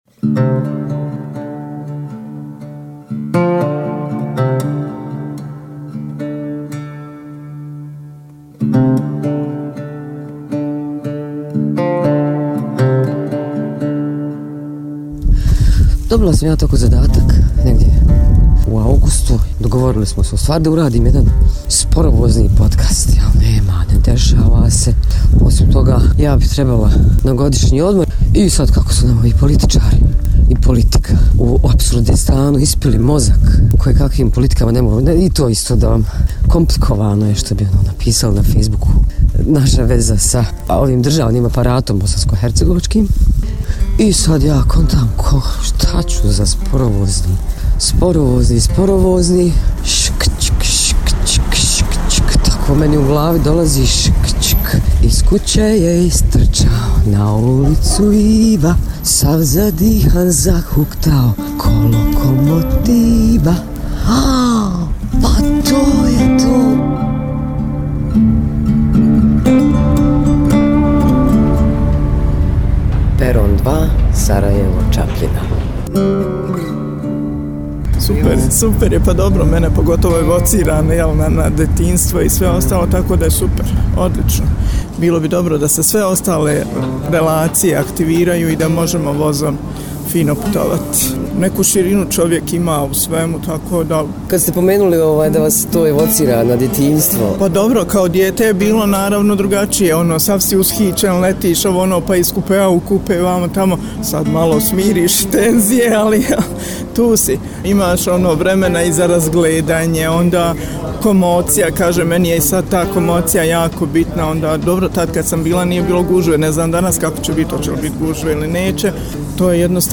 Putujemo i družimo se sa putnicima i osobljem u vozu koji ide na jug, od Sarajeva ka Čapljini.